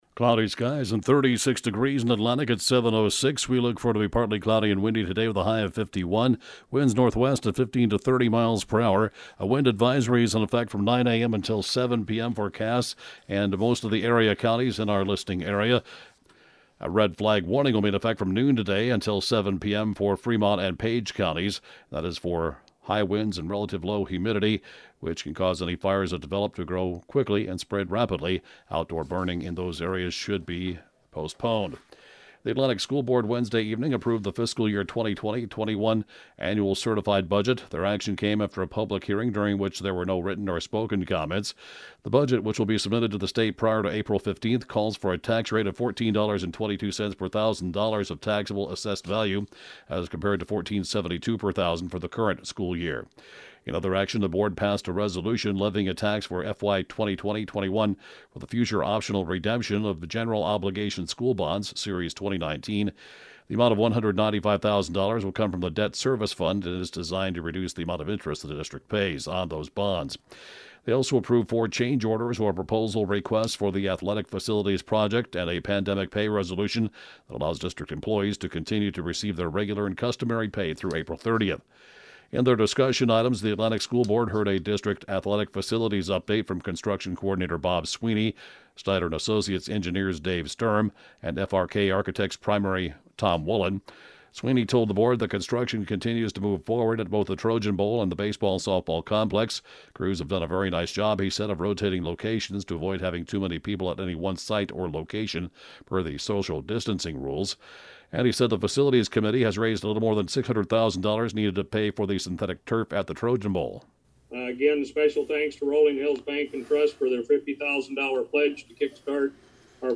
(Podcast) KJAN Morning News & Funeral report, 12/21/2018